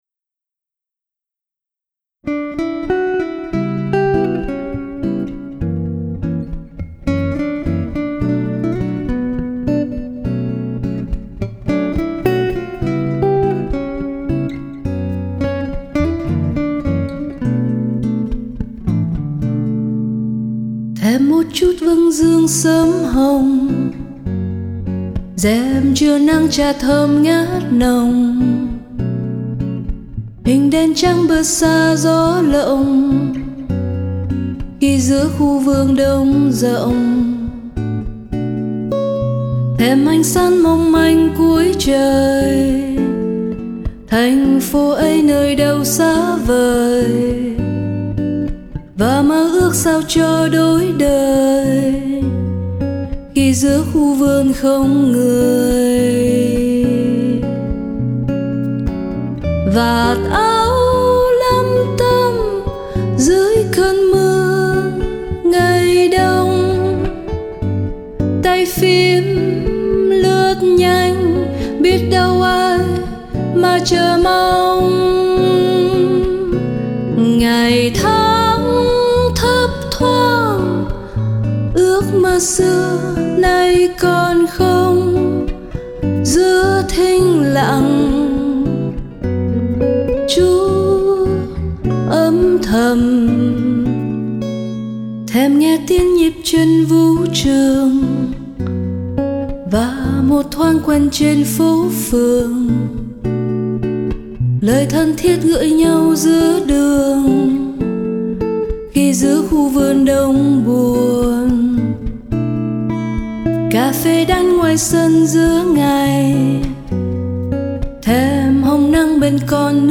Tiếng tây ban cầm
Tiếng đàn điêu luyện, ngọt ngào trong tiết tấu bossa nova